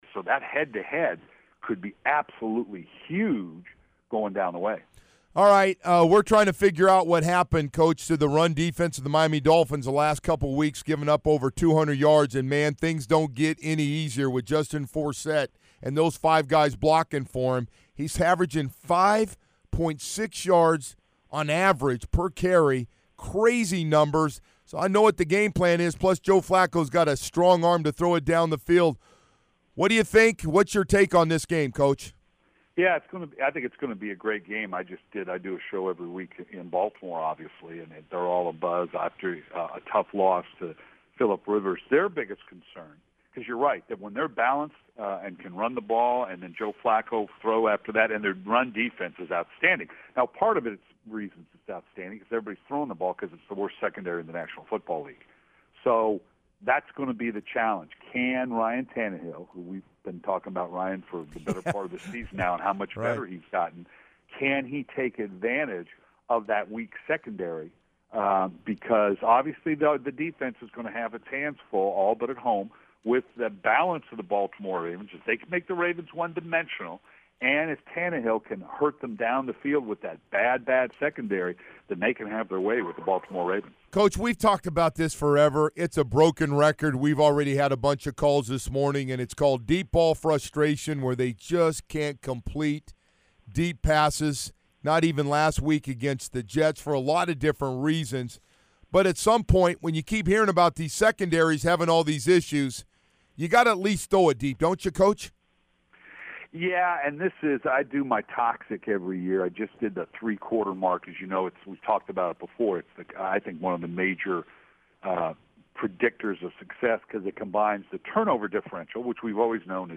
NFL Network analyst and former head coach Brian Billick joined the Joe Rose Show this morning to talk all things NFL and shed light on the monstrous matchup between the Ravens and Dolphins this Sunday. He also dropped some Jim Harbaugh nuggets. Listen to the interview here.